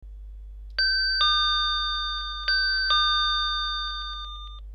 • počet melódií: 3 (Ding Dong, Ding Dong Dong, Big Ben)
Bezdrotovy-zvoncek-melodia-11.mp3